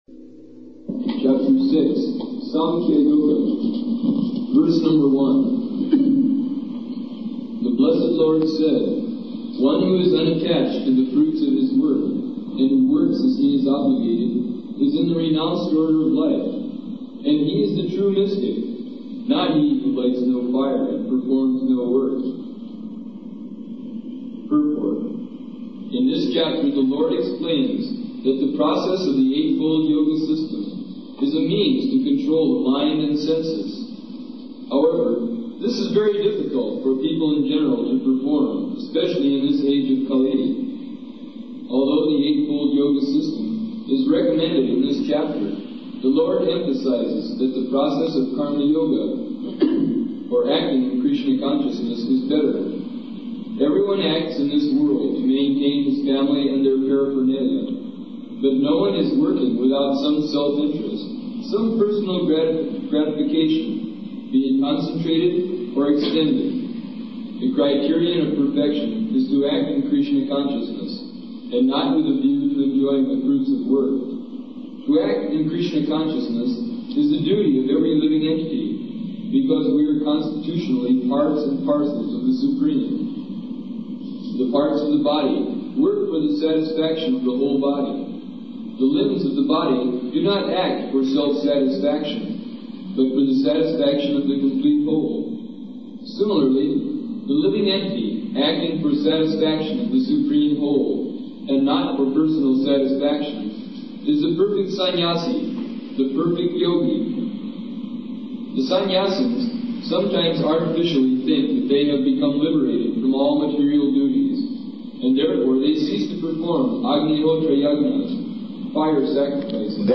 Bhagavad-gītā 6.1 [Sāṅkhya Yoga System] --:-- --:-- Type: Bhagavad-gita Dated: February 13th 1969 Location: Los Angeles Audio file: 690213BG-LOS_ANGELES.mp3 Devotee: Chapter Six, Sankhya Yoga .